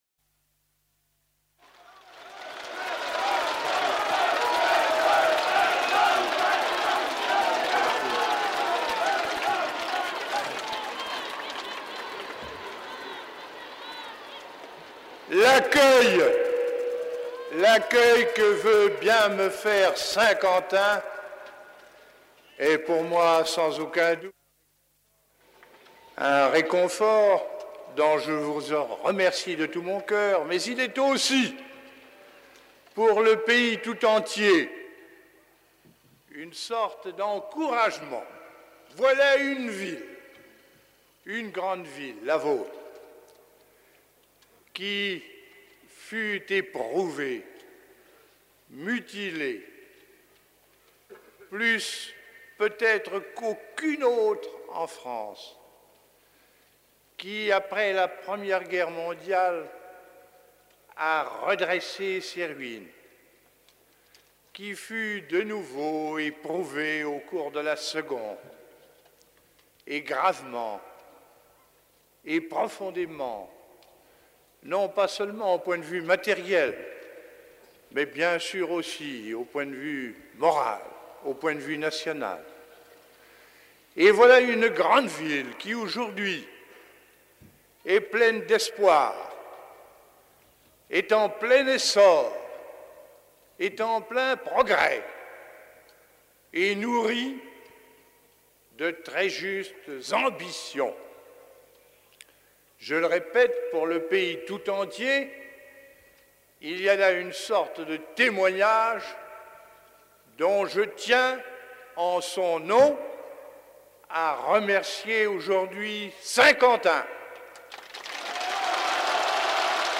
Écouter le discours du général de Gaulle à Saint-Quentin, le 12 juin 1964